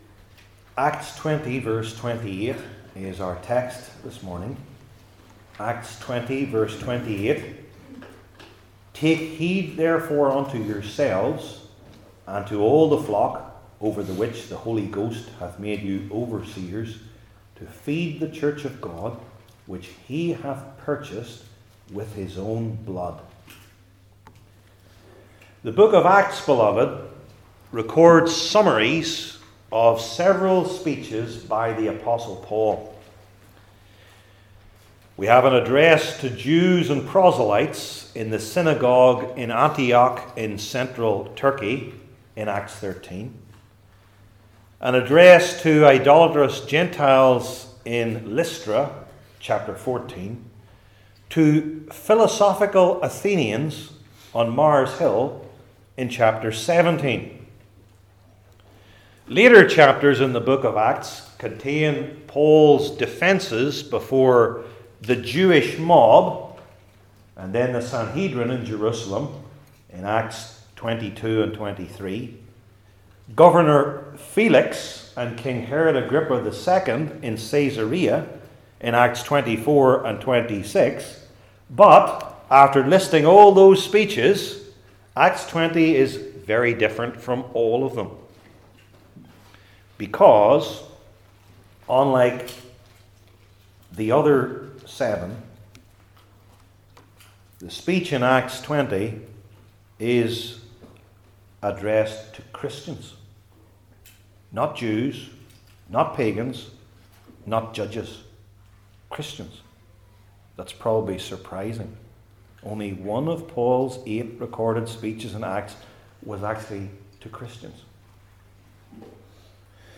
New Testament Individual Sermons I. Their Office II.